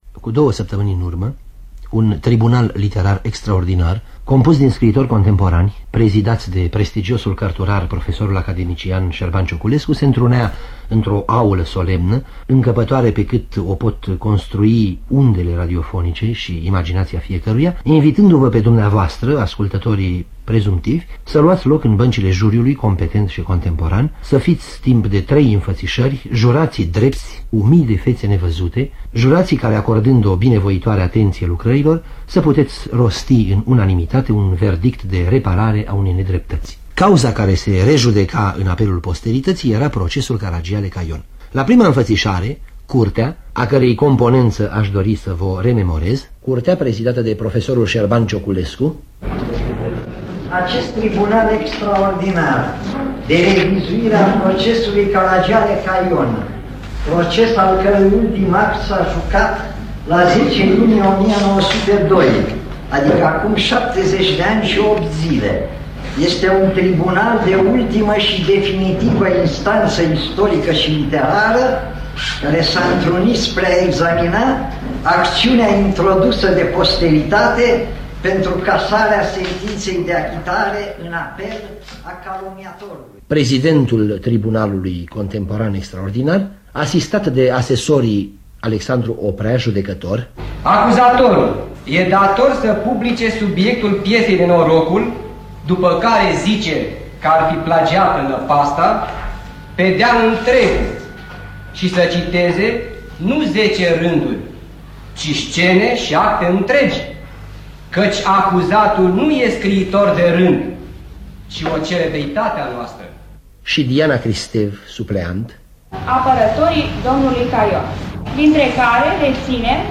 Partea a 3-a. Regia, scenariul radiofonic și comentariul: Romulus Vulpescu.